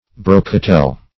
Brocatel \Bro"ca*tel\, n. [F. brocatelle, fr. It. brocatello: